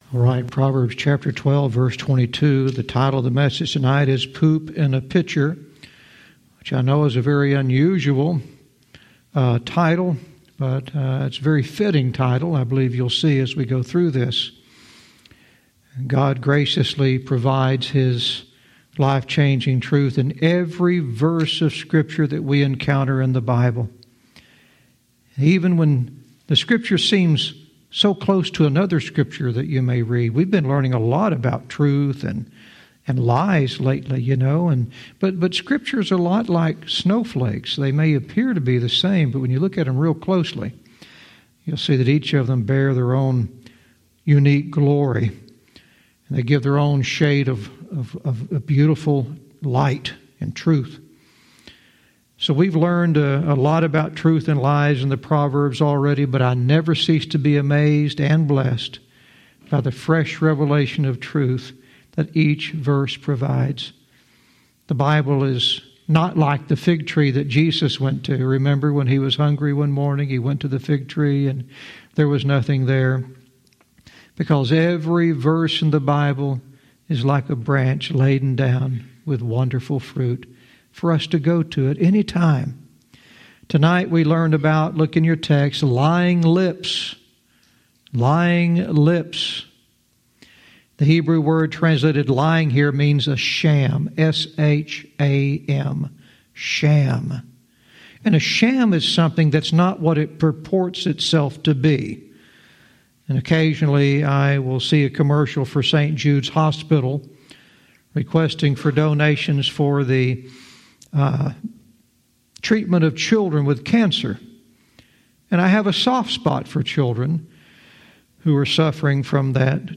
Verse by verse teaching - Proverbs 12:22 "Poop In a Pitcher"